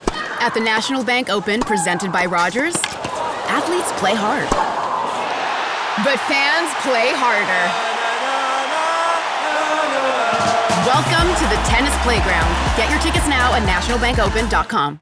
Commercial (Tennis Canada) - EN